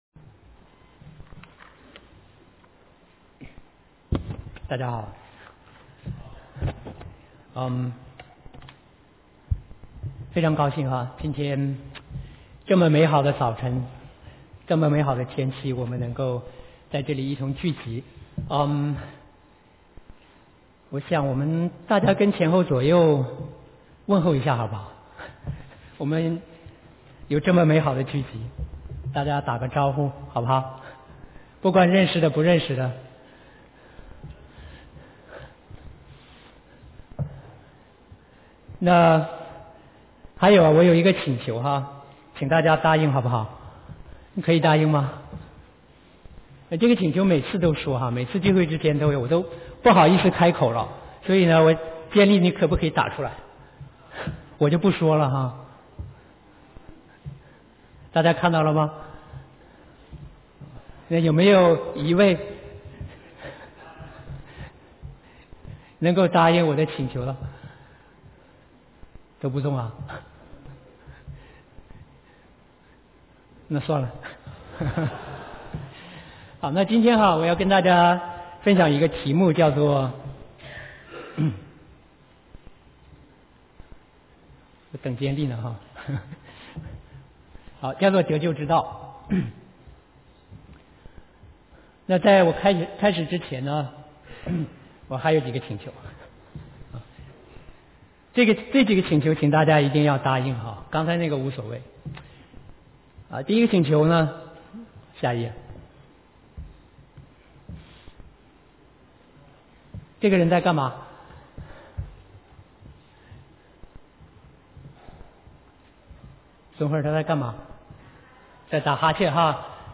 Gospel Meeting